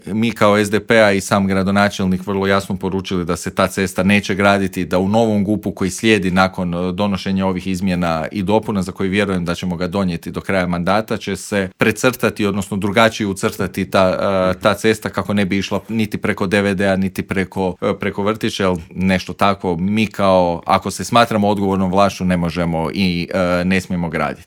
O ovim i drugim gradskim temama u Intervjuu Media servisa smo razgovarali s predsjednikom Gradske skupštine iz redova SDP-a, Matejem Mišićem.